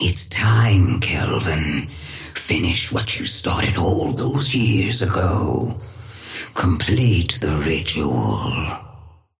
Sapphire Flame voice line - It's time, Kelvin, finish what you started all those years ago. Complete the ritual.
Patron_female_ally_kelvin_start_03.mp3